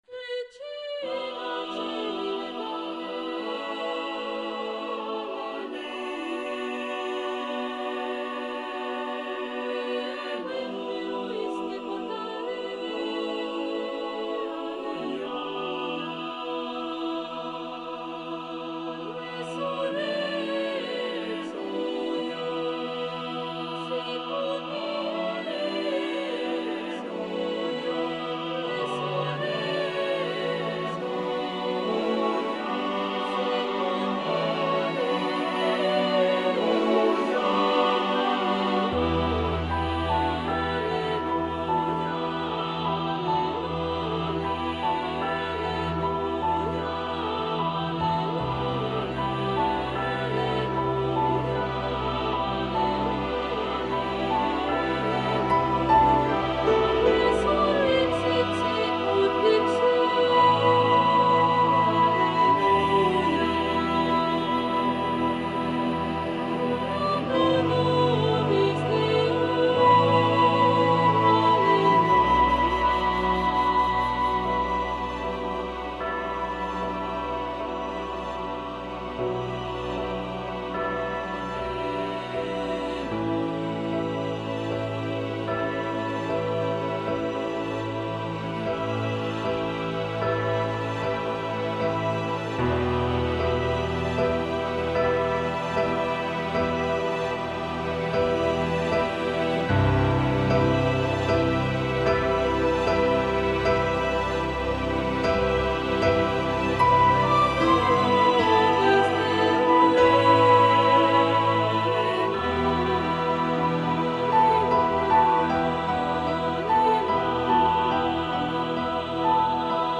demo     notový materiál     partitura